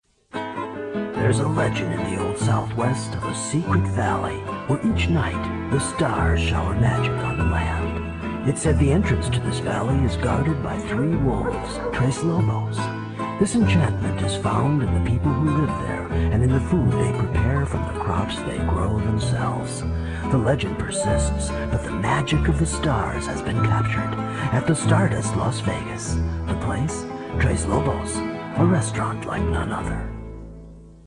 Various Radio Commercial Work